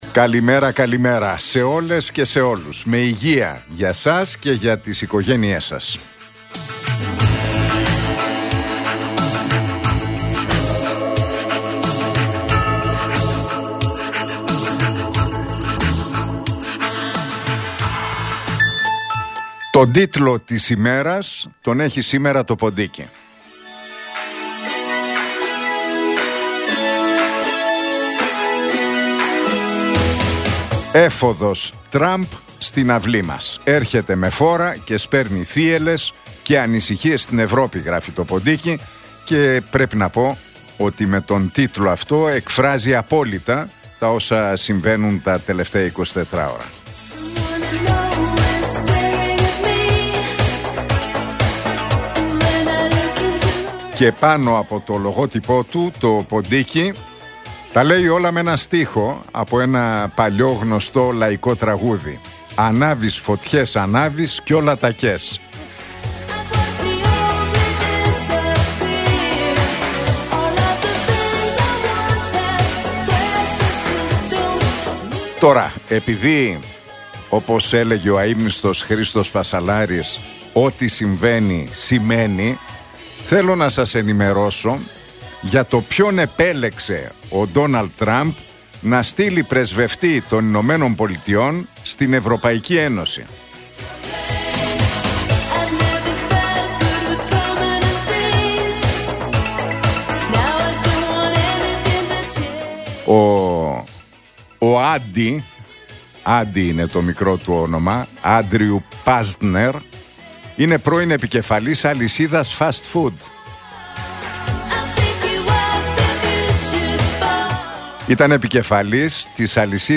Ακούστε το σχόλιο του Νίκου Χατζηνικολάου στον ραδιοφωνικό σταθμό RealFm 97,8, την Πέμπτη 23 Ιανουαρίου 2025.